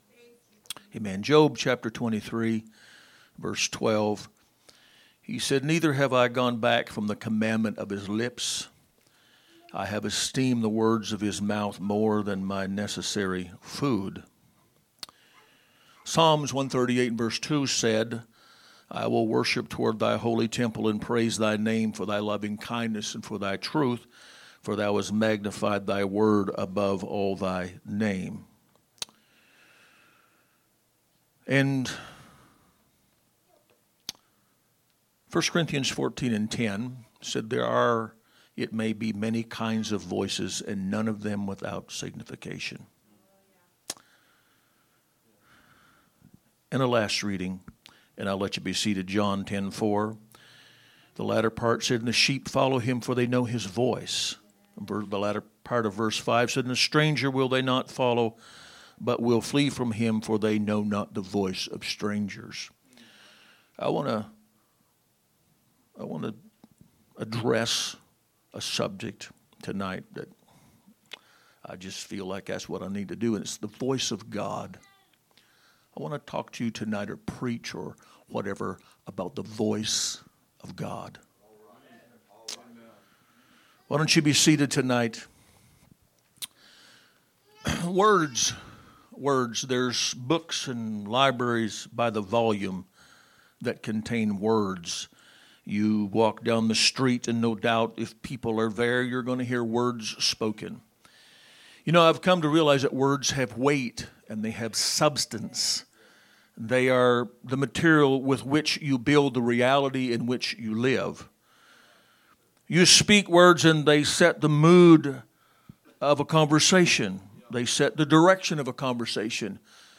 6/8/2025 Sunday Evening Service